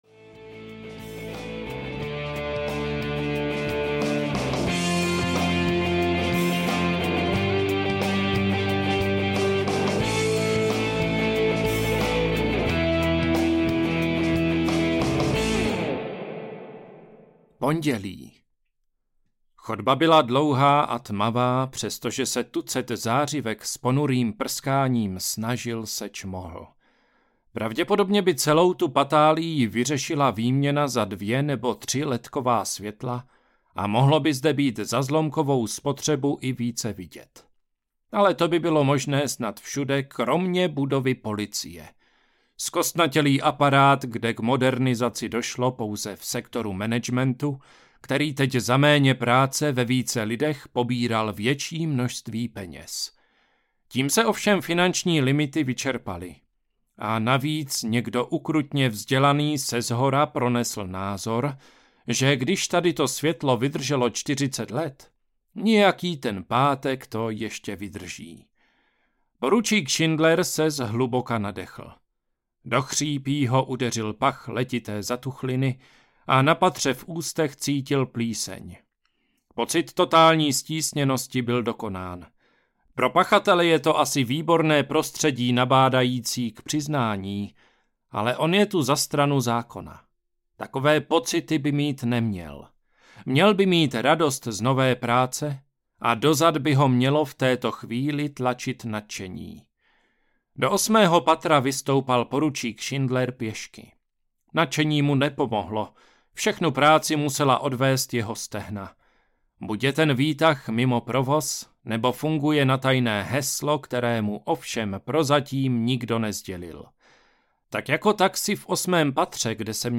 Ukázka z knihy
kdo-zabil-tu-babu-audiokniha